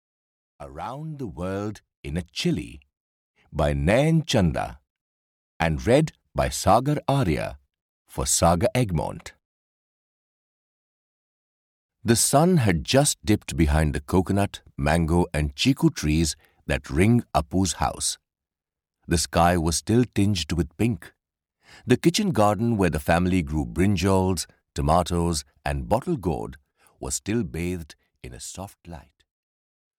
Around the World With a Chilli (EN) audiokniha
Ukázka z knihy